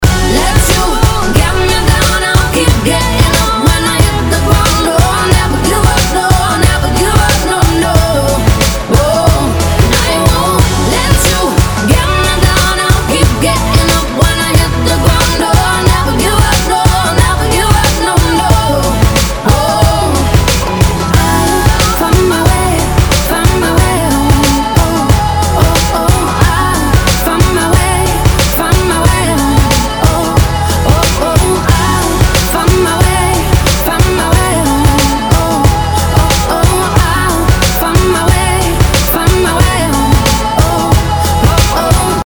• Качество: 320, Stereo
поп
женский голос
dance
RnB
vocal